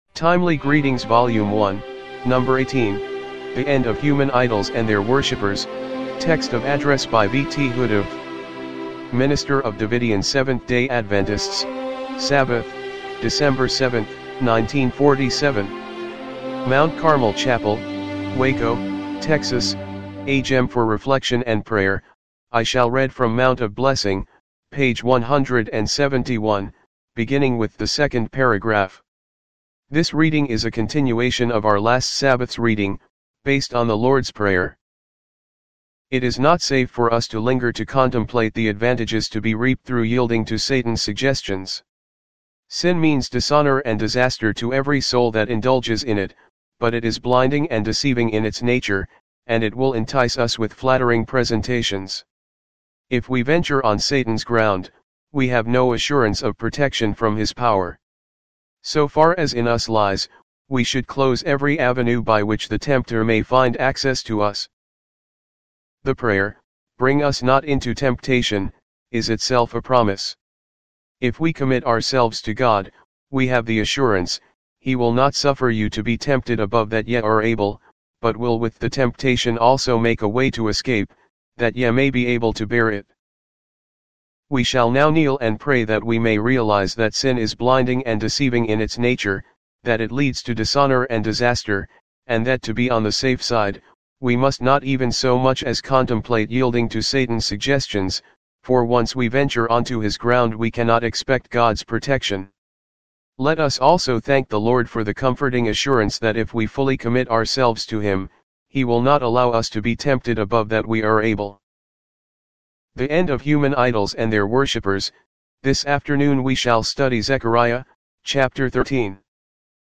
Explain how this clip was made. timely-greetings-volume-1-no.-18-mono-mp3.mp3